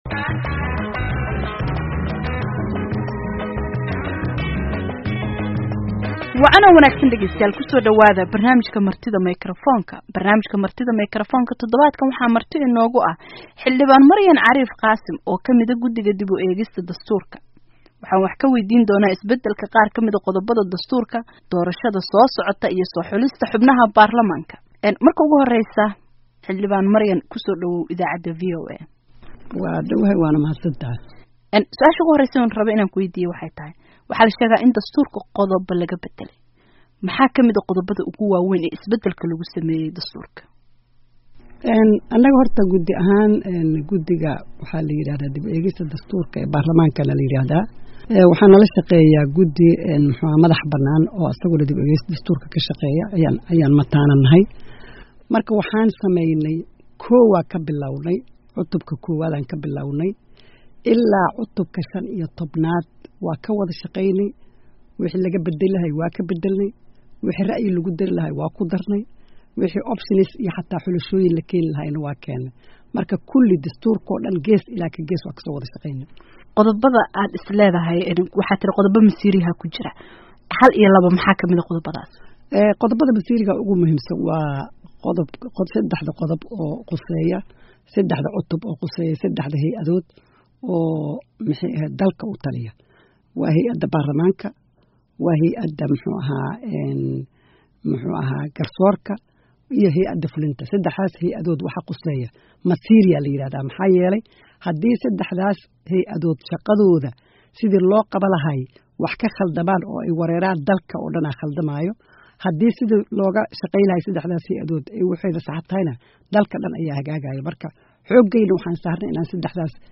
Dalkan Maraykanka waxaa ku sugan Xildhibaan Maryan Careef oo ka mida mudanayaasha golaha shacabka ee Soomaaliya, waxay dhawaan nagu soo booqatay daarta VOA ee Washington.